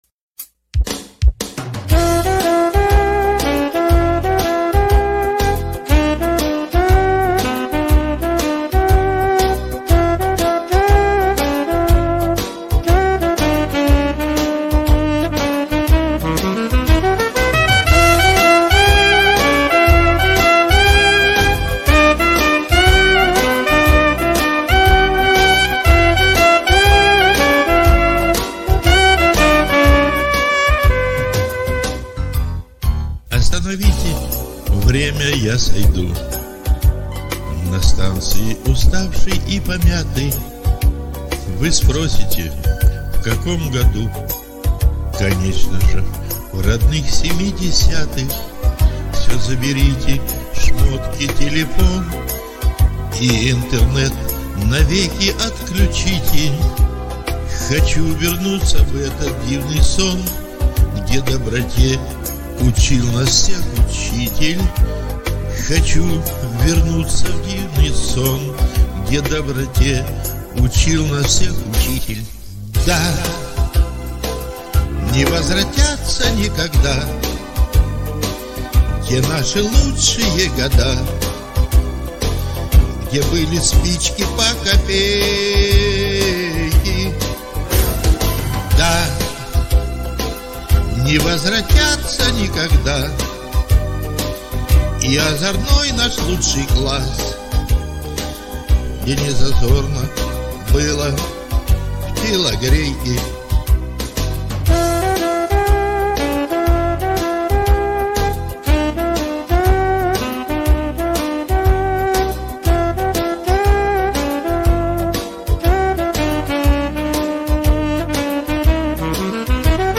неподправена баладична емоция
морски хит